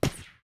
gun shot 00.ogg